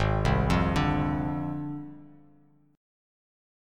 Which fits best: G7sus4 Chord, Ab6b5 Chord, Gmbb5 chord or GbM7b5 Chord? Ab6b5 Chord